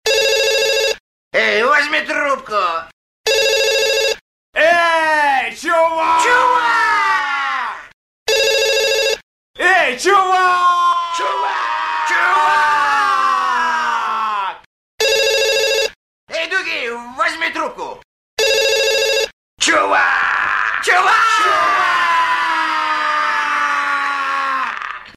And that's the Dude scream.
• Category: Men's scream
• Quality: High